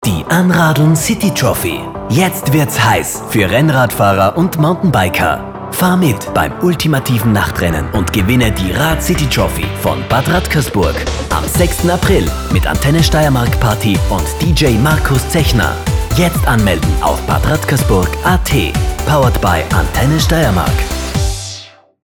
Für meine Aufnahmen im eigenen Studio habe ich einen professionellen Aufnahmeraum für beste Raumakustik und ungestörtes Arbeiten.
Radiospots
Anradeln_AntenneSpot_V3.mp3